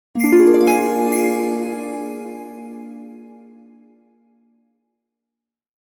Joyful Holiday Whoosh Melody Transition Sound Effect
Sleigh bells, harp, and sparkling chimes create a magical festive whoosh. This sound adds a smooth, joyful holiday transition and brings warmth to any scene.
Genres: Sound Logo
Joyful-holiday-whoosh-melody-transition-sound-effect.mp3